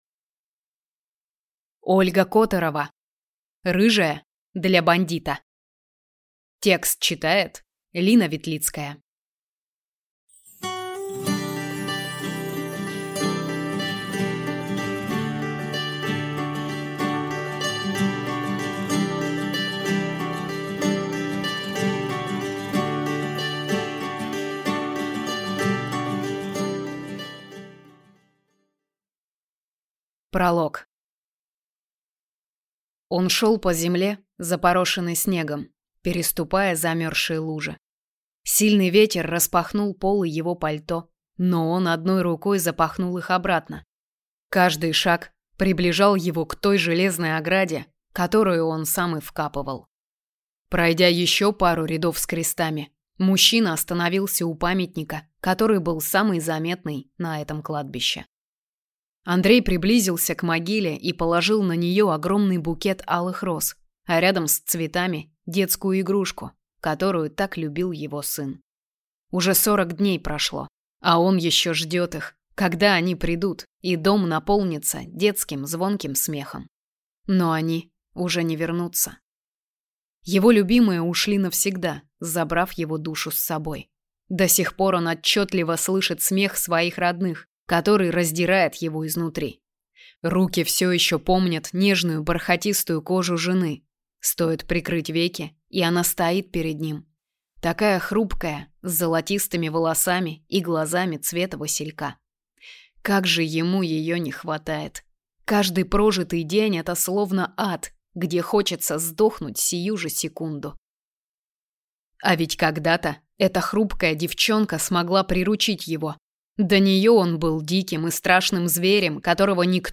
Аудиокнига Рыжая для бандита | Библиотека аудиокниг
Прослушать и бесплатно скачать фрагмент аудиокниги